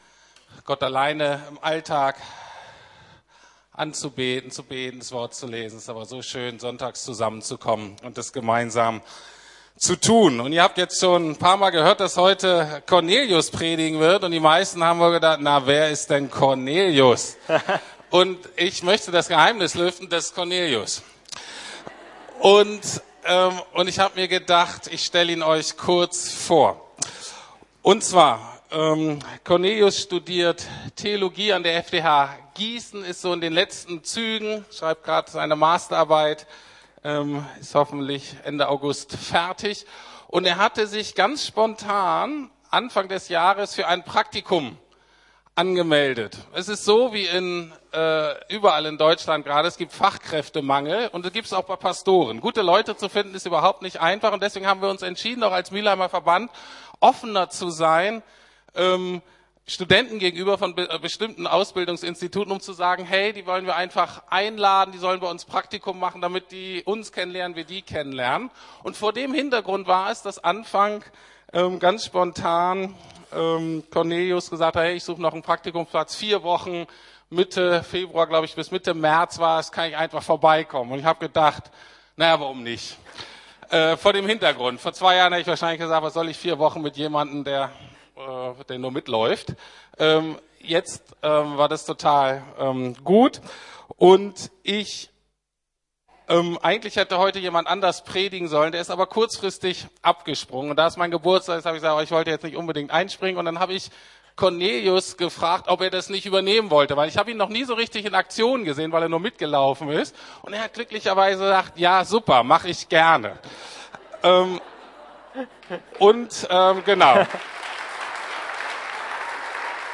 Paulus - Dankbar trotz Enttäuschungen ~ Predigten der LUKAS GEMEINDE Podcast